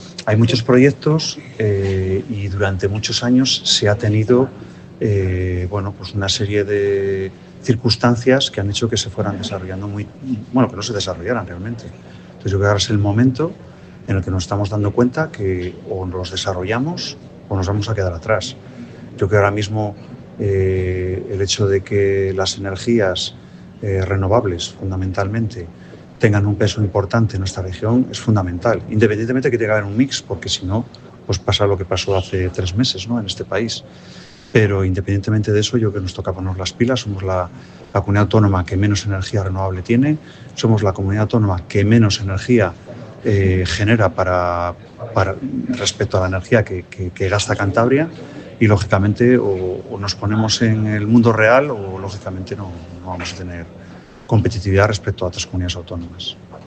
Jornada Energía en Cantabria, situación actual y renovables - CEOE-CEPYME